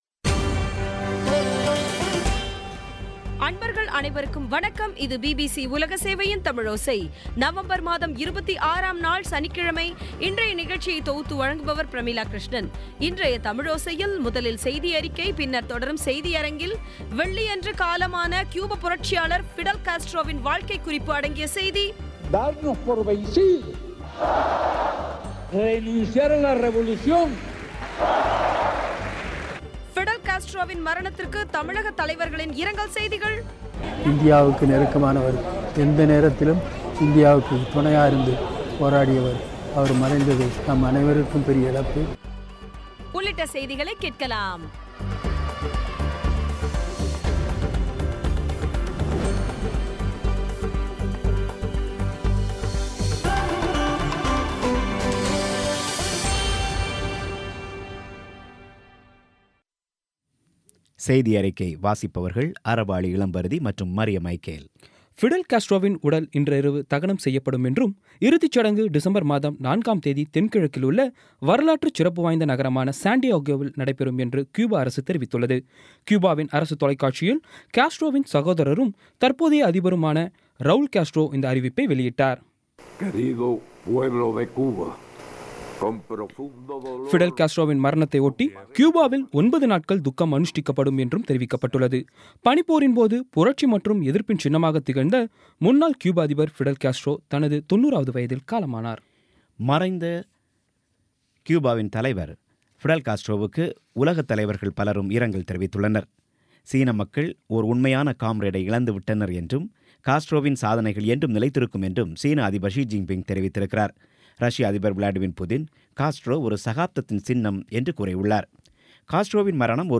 இன்றைய தமிழோசையில், முதலில் செய்தியறிக்கை, பின்னர் தொடரும் செய்தியரங்கத்தில் வெள்ளியன்று காலமான கியூப புரட்சியாளர் ஃபிடல் காஸ்ட்ரோவின் வாழ்க்கை குறிப்பு அடங்கிய செய்தி ஃபிடல் காஸ்ட்ரோவின் மரணத்திற்கு தமிழக தலைவர்களின் இரங்கல் செய்திகள் உள்ளிட்ட செய்திகளை கேட்கலாம்